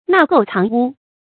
納垢藏污 注音： ㄣㄚˋ ㄍㄡˋ ㄘㄤˊ ㄨ 讀音讀法： 意思解釋： 垢、污：骯臟的東西。比喻隱藏或包容壞人壞事。